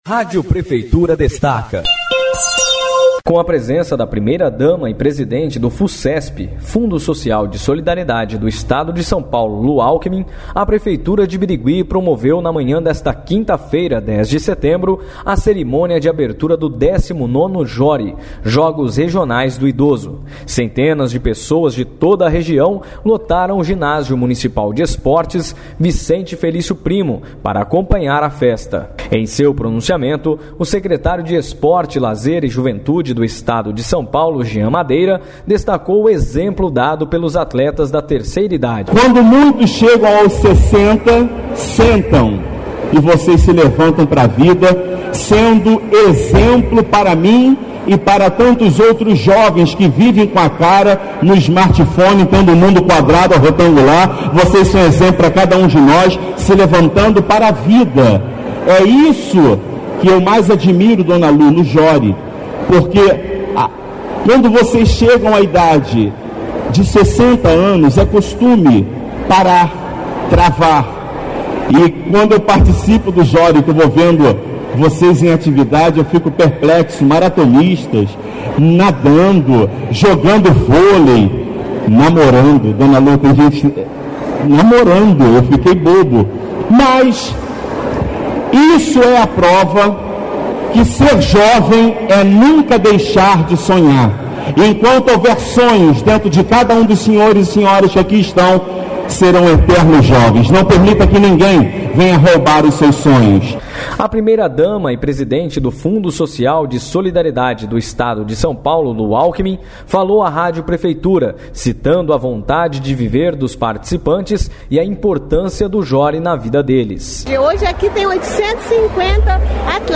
A primeira-dama do Estado, Lu Alckmin, e o secretário estadual de Esporte, Lazer e Juventude, Jean Madeira, falaram sobre o JORI.